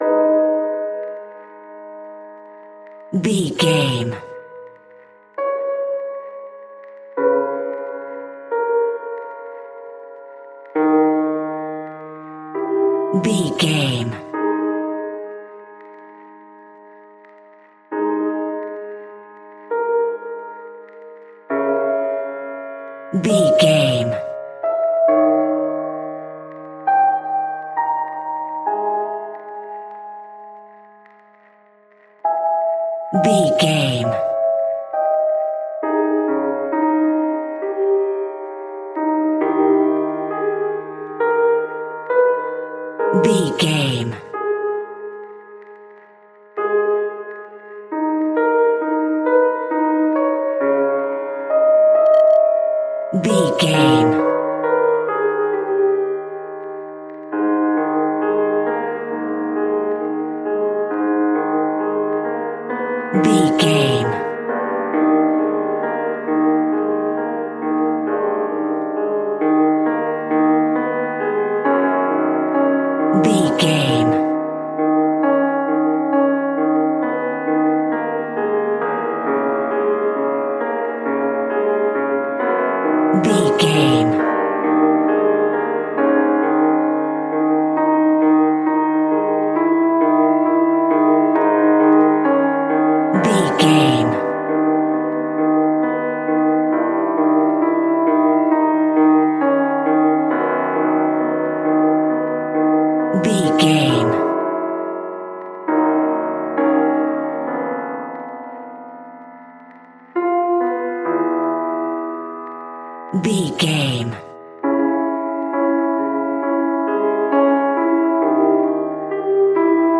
Thriller
Aeolian/Minor
G#
Slow
scary
ominous
dark
suspense
haunting
eerie
horror